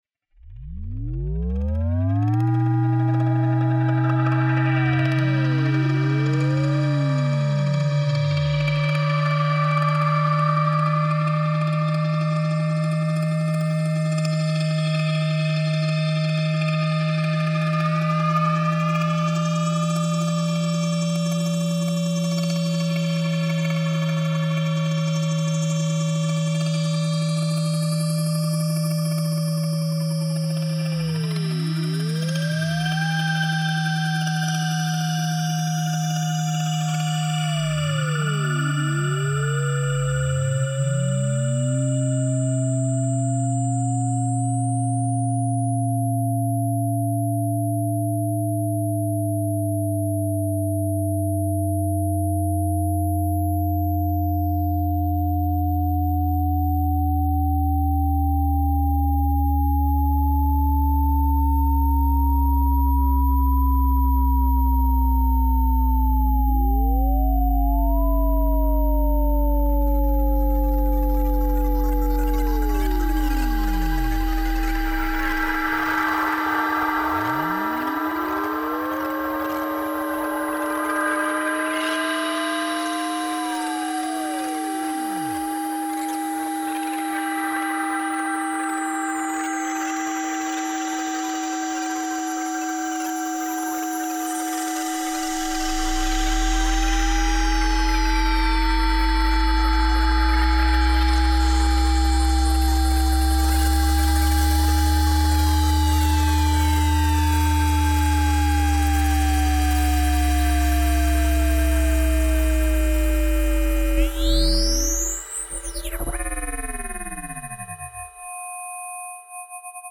Electroacoustic Music Based On A Graphic Score
Pitch is determined according to the contours of the landscape and the resulting tones are modulated based on the height of the sun. north pole.mp3 Graphic Score